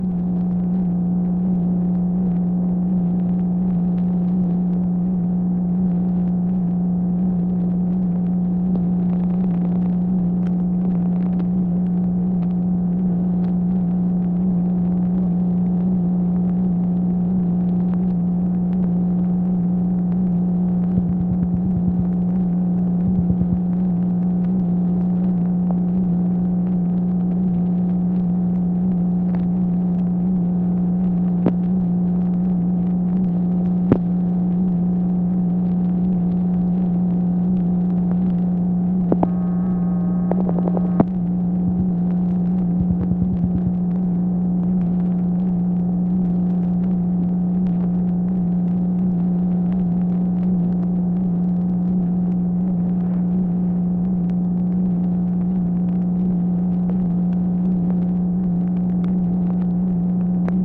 MACHINE NOISE, January 6, 1966
Secret White House Tapes